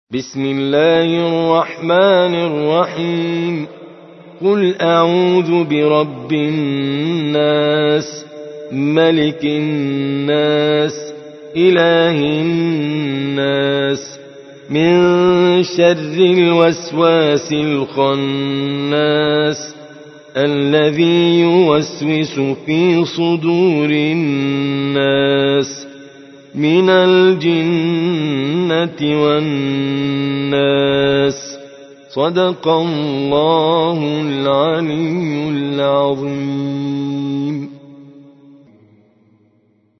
114. سورة الناس / القارئ